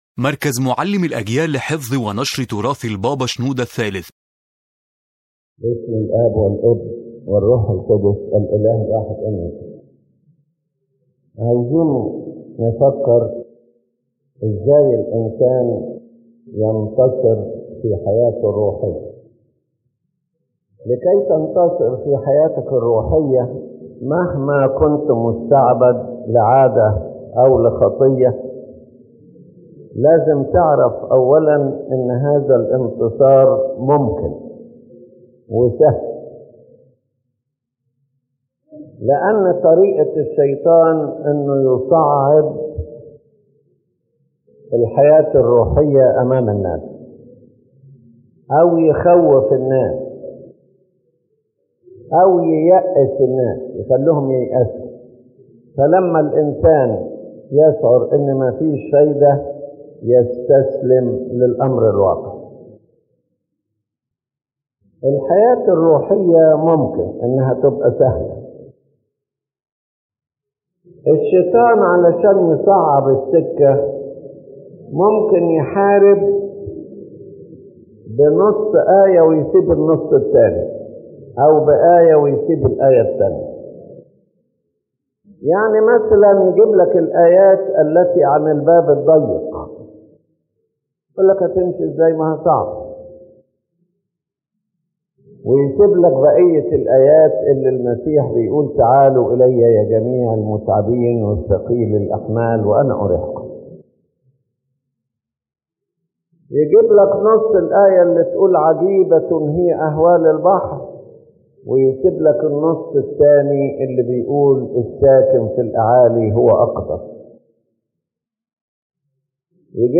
The lecture explains that spiritual victory is possible for every person, no matter how weak they are or how deep their sin may be, because true strength does not come from the human being but from God.